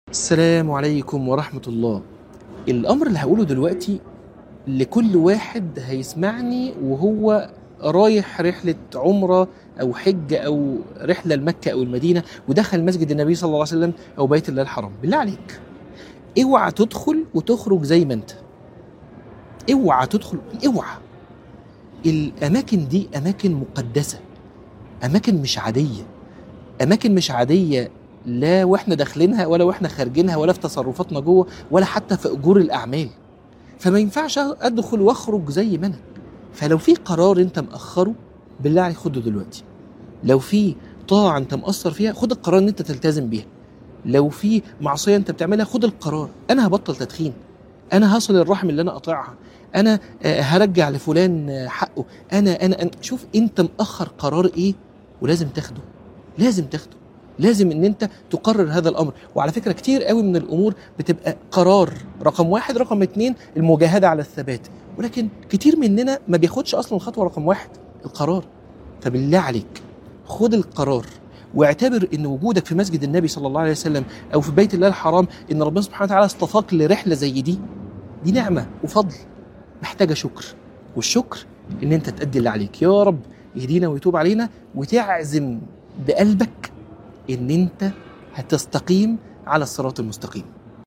عنوان المادة اوعى تمشي من هنا زي ما جيت - من الحرم